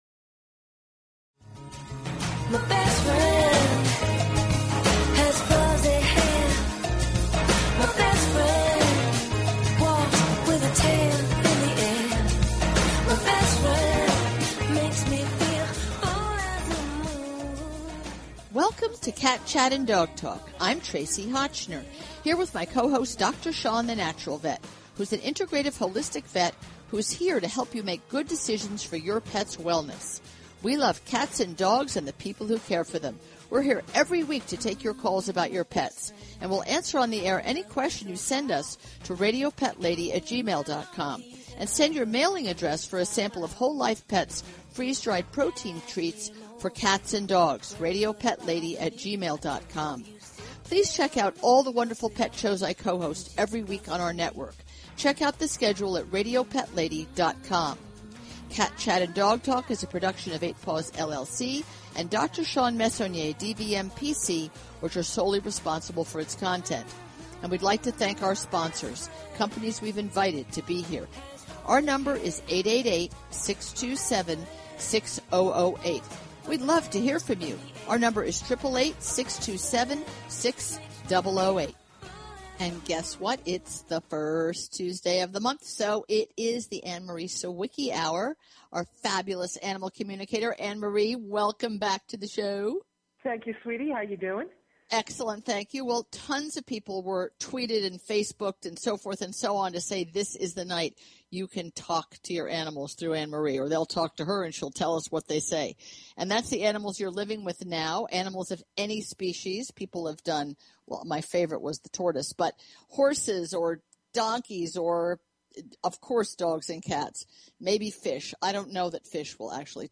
Talk Show Episode, Audio Podcast, Cat_Chat_and_Dog_Talk and Courtesy of BBS Radio on , show guests , about , categorized as